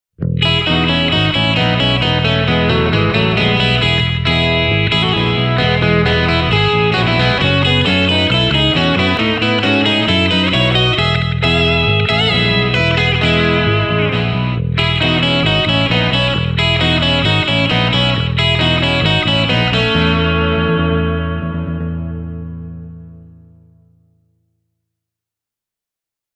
Käytin demobiisissä molempia TST-50 Relic -versioita.